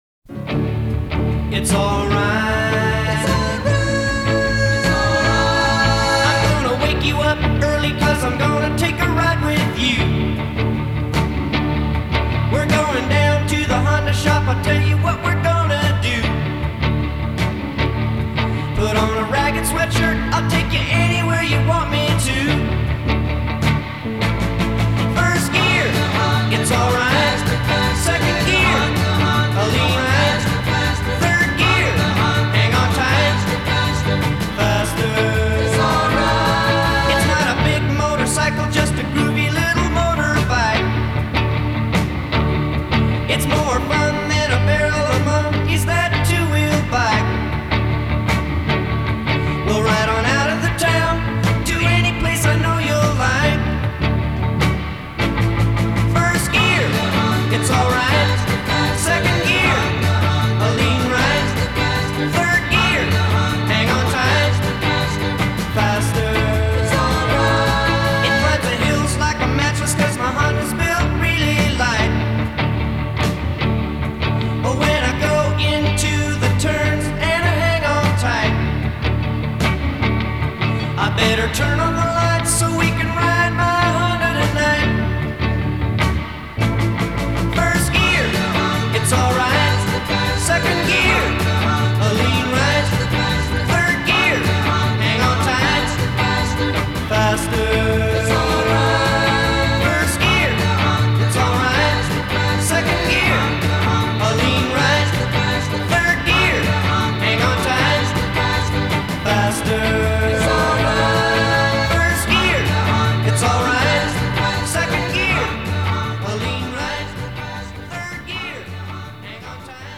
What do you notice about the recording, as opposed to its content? This is the stereo version.